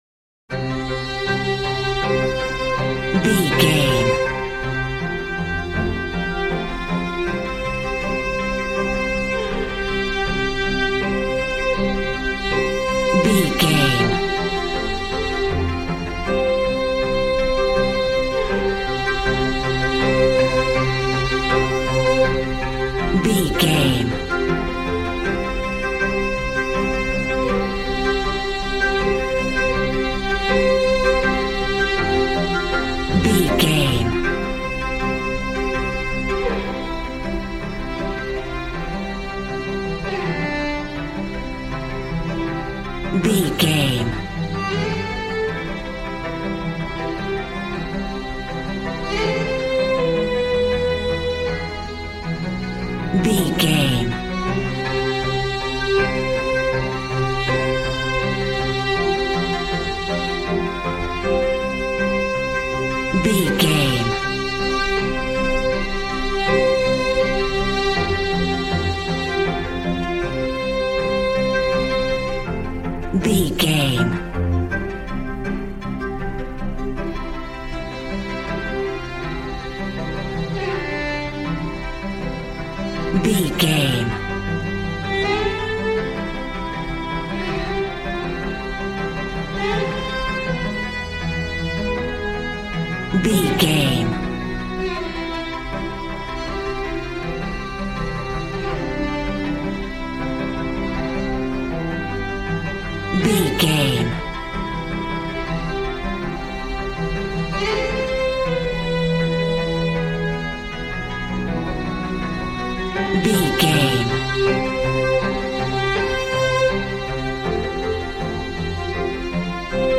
Ionian/Major
regal